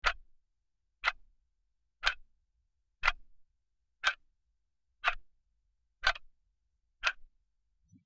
Clock Ticking
Clock_ticking.mp3